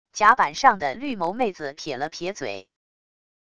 甲板上的绿眸妹子撇了撇嘴wav音频生成系统WAV Audio Player